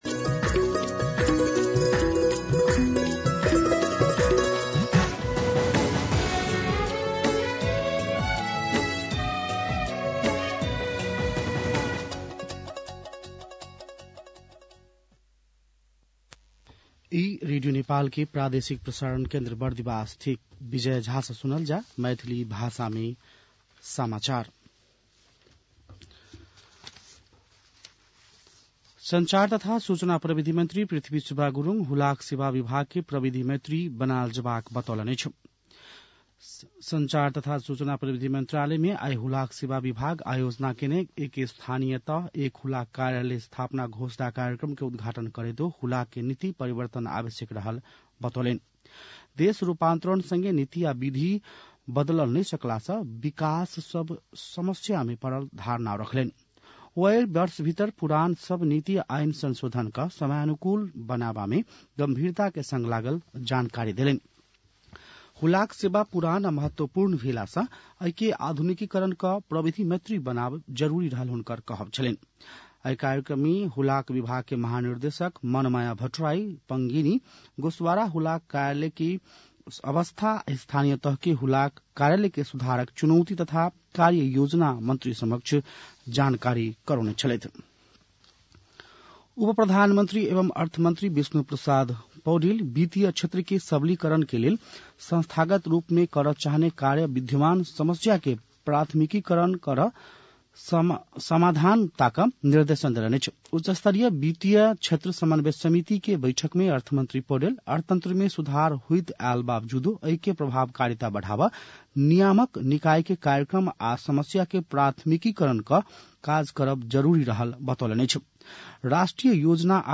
मैथिली भाषामा समाचार : १४ माघ , २०८१